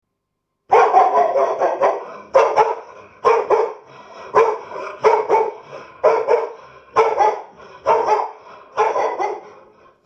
Dog Barking #1 | TLIU Studios
Category: Animal Mood: Alerting Editor's Choice